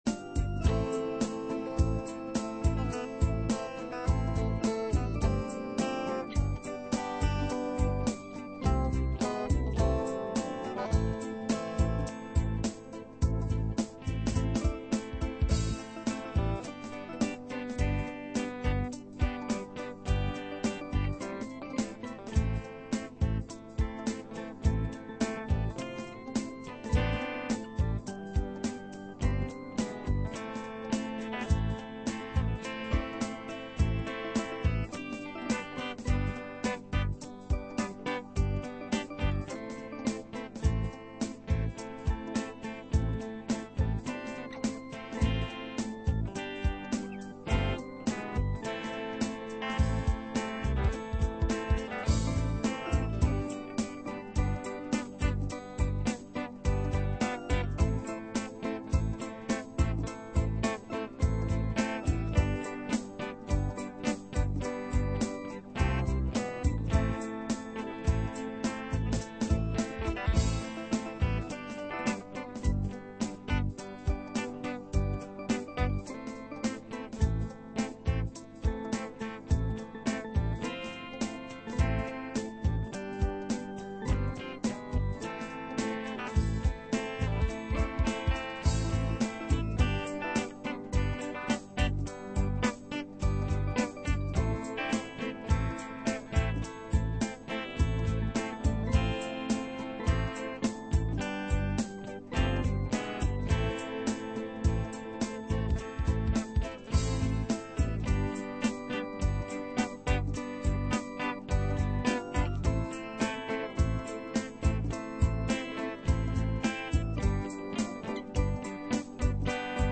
Мы в школе играли.